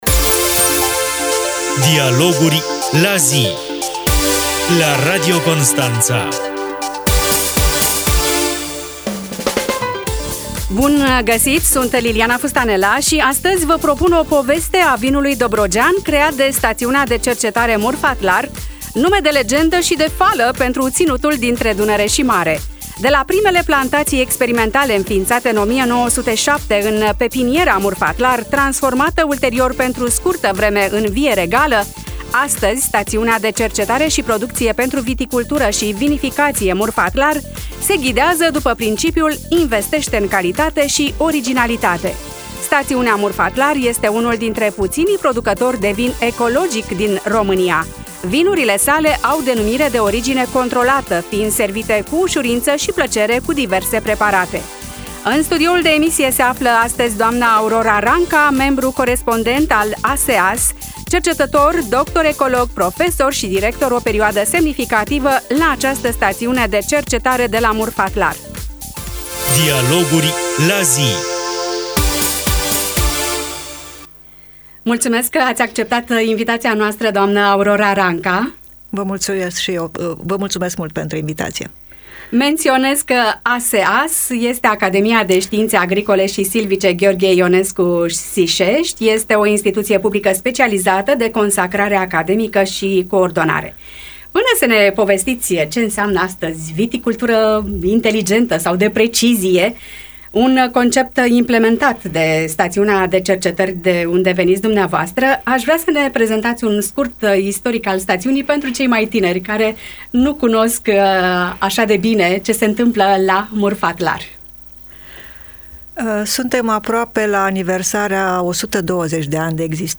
Dialoguri la zi: Viticultură de precizie, ferme viticole inteligente - o discuție despre cercetare și tehnologii în viticultură - Știri Constanța - Radio Constanța - Știri Tulcea